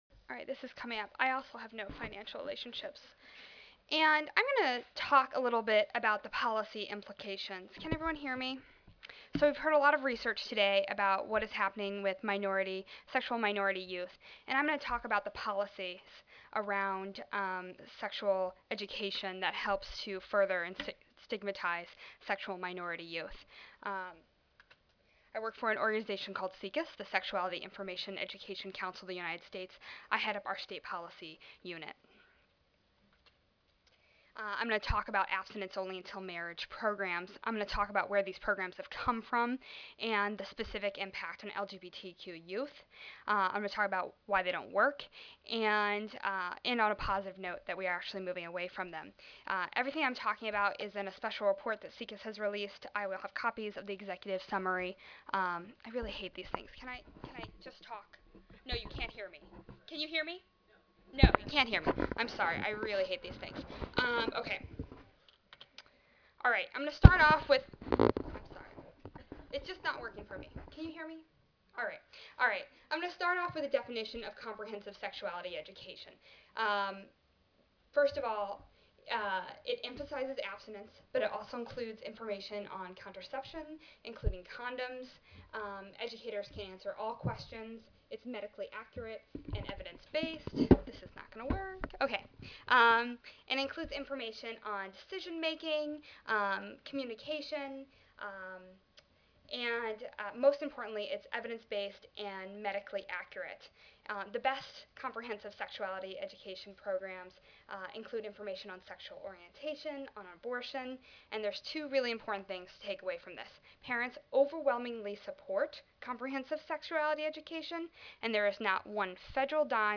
3358.0 GLBT Youth : Meeting the Health Needs of All Students Monday, October 27, 2008: 2:30 PM Oral This session will be devoted to research examining the particular health needs of lesbian, gay, bisexual, transgender and questioning (LGBTQ) youth.